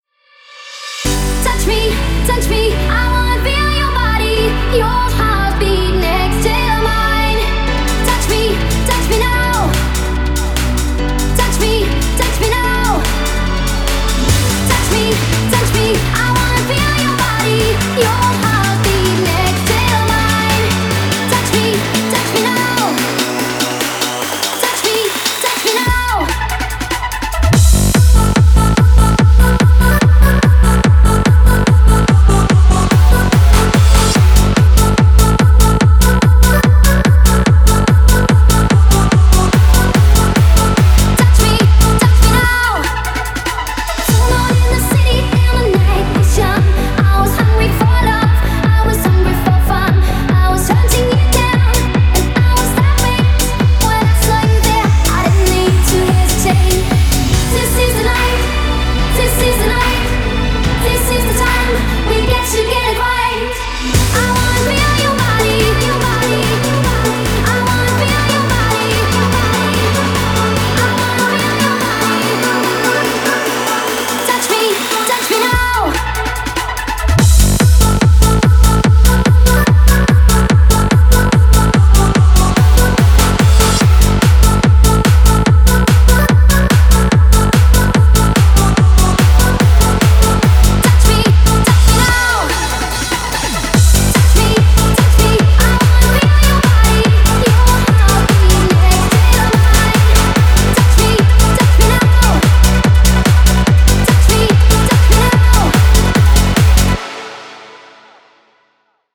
Клубная музыка
клубные ремиксы